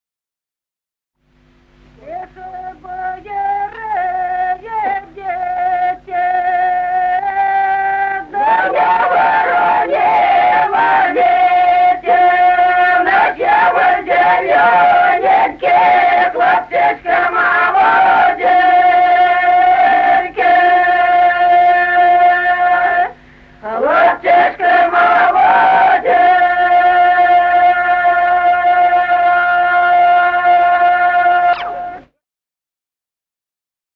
Народные песни Стародубского района «Тихо, бояры, идите», свадебная.
запев
подголосник
с. Остроглядово.